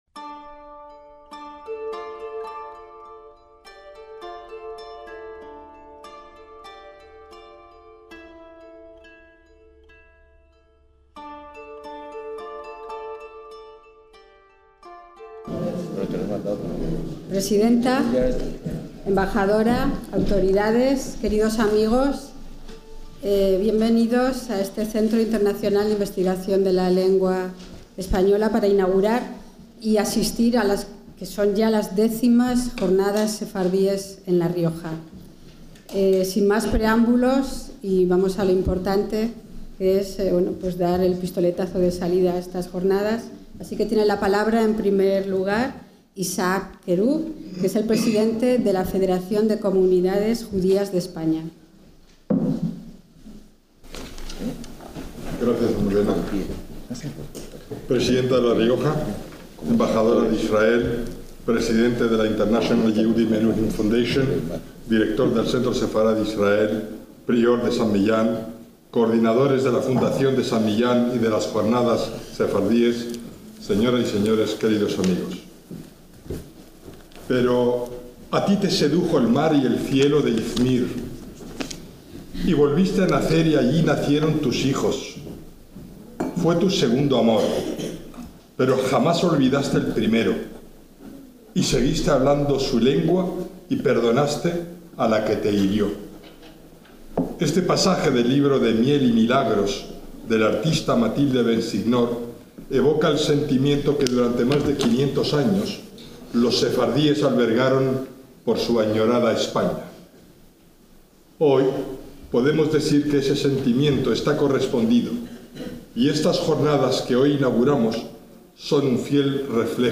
Sesión y conferencia inaugural